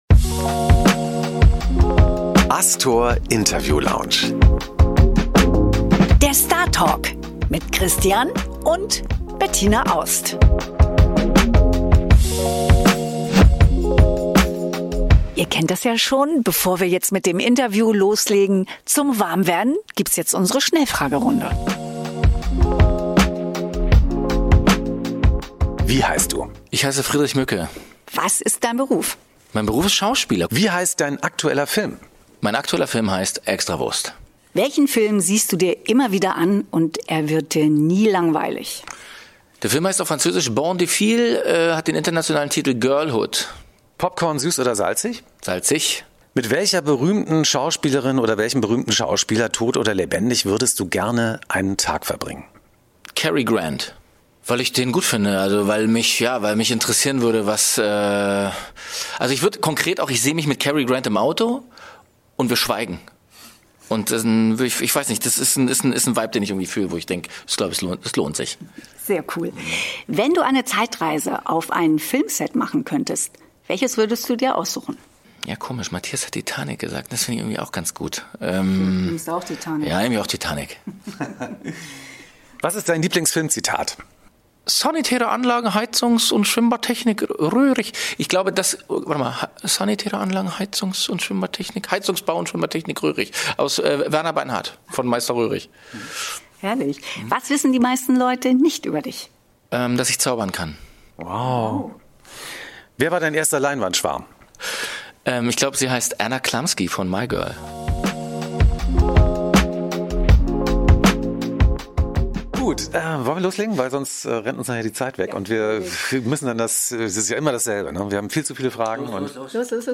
Friedrich Mücke im Interview: Der Schauspieler über seinen Film „Extrawurst“, Hape Kerkeling, Jazz und katholischen Fußball.